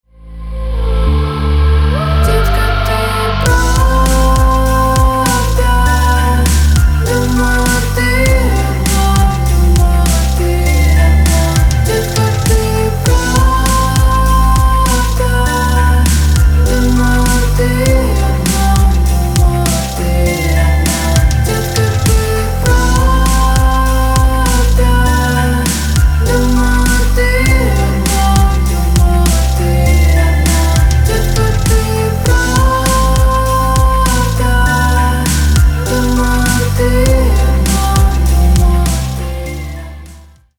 Рок Металл
спокойные